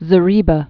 (zə-rēbə)